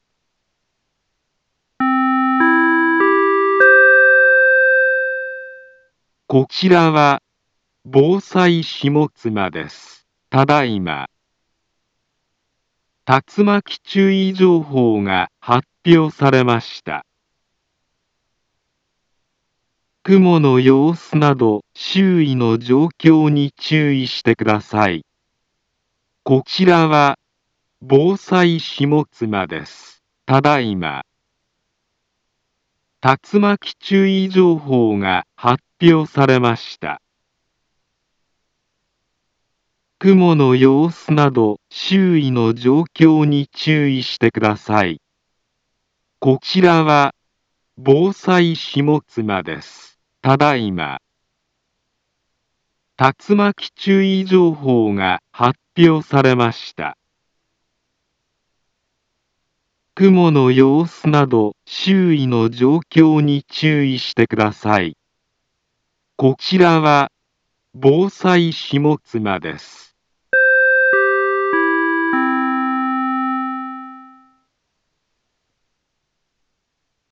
Back Home Ｊアラート情報 音声放送 再生 災害情報 カテゴリ：J-ALERT 登録日時：2022-08-13 22:05:08 インフォメーション：茨城県南部は、竜巻などの激しい突風が発生しやすい気象状況になっています。